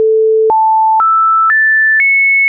Ülemises reas siinushelid sagedusega 1) 440, 2) 880, 3) 1320, 4) 1760, 5) 2200 Hz, alumises reas liidetuna: 1) ainult põhitoon, 2) ühe, 3) kahe, 4) kolme ja 5) nelja ülemheliga.
siinus440X5.wav